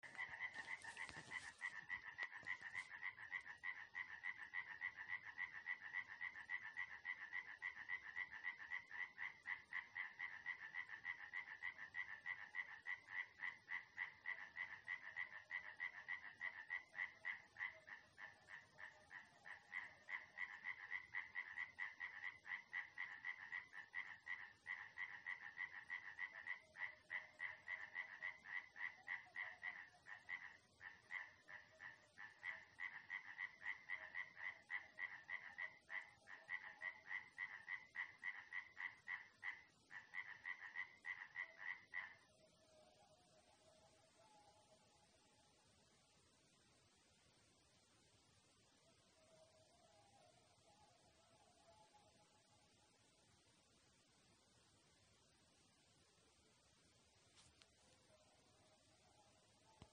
Varde (nenoteikta), Rana sp.
Administratīvā teritorijaAlsungas novads
СтатусПоёт